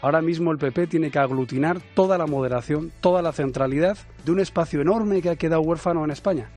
Declaraciones de Pablo Casado en el programa de Herrera en COPE